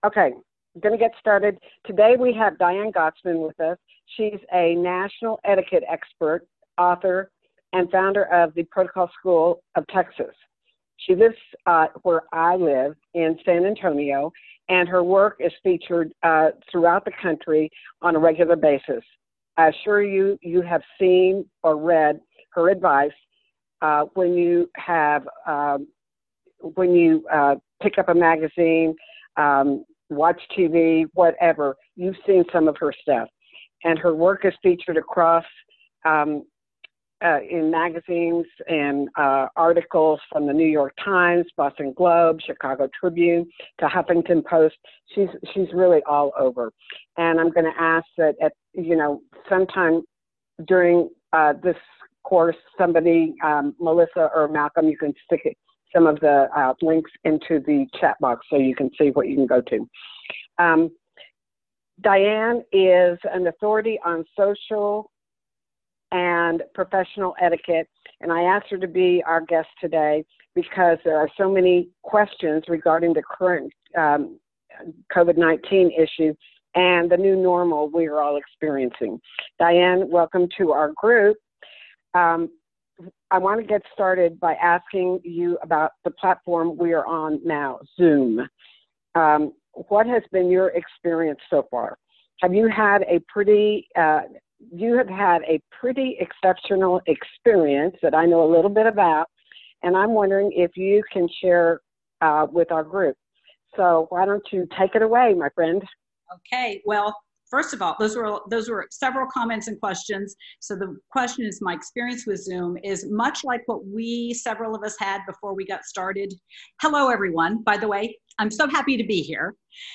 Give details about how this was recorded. Online Conference with Protocol School of Texas